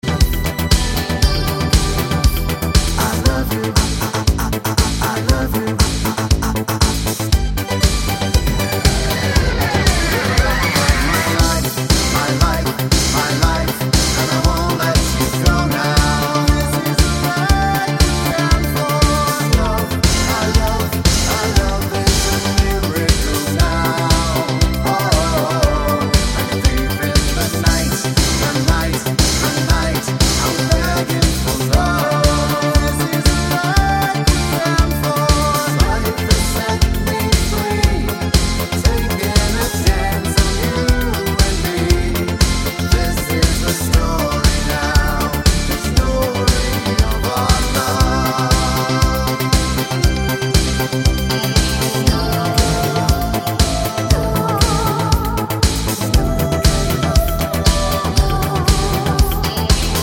With Chorus BV Line In Pop (1980s) 4:09 Buy £1.50